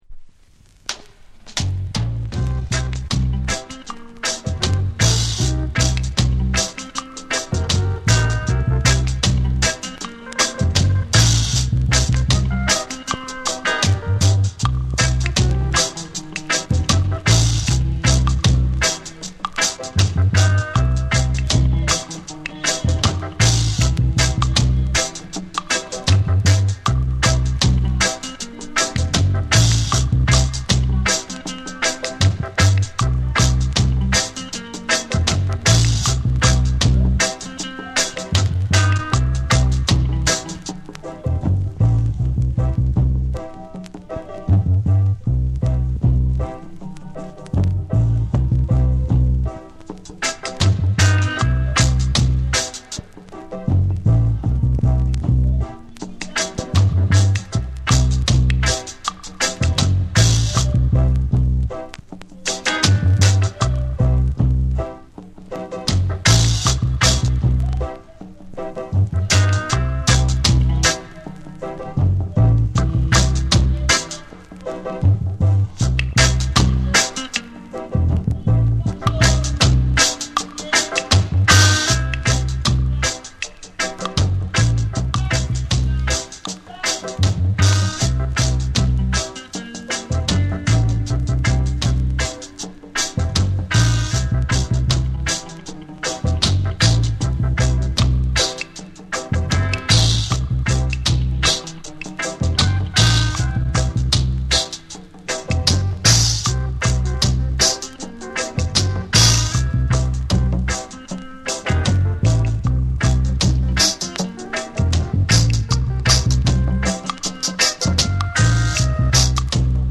深く沈み込むベースラインと、エコー/リバーブを駆使した空間的なミックスが印象的ダブ・アルバム。
ジャマイカ盤特有のチリノイズが入ります。